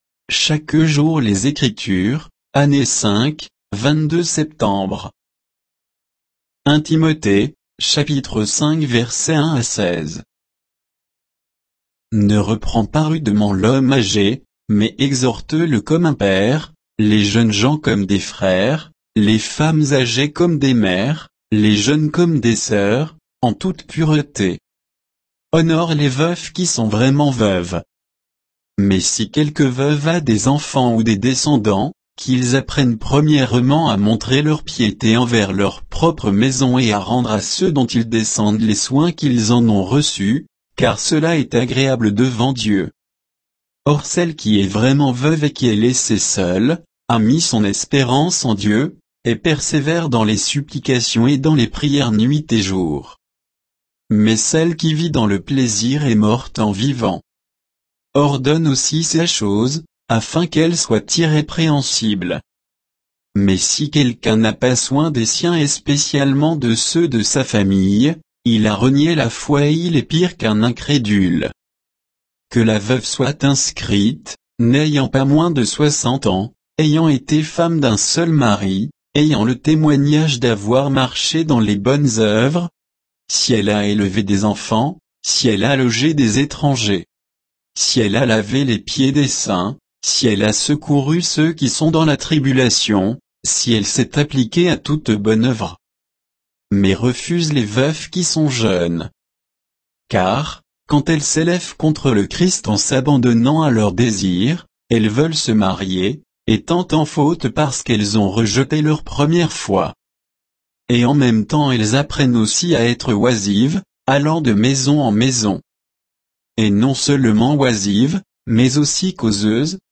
Méditation quoditienne de Chaque jour les Écritures sur 1 Timothée 5